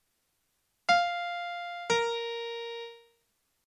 Töne nacheinander gespielt
Intervall_E.mp3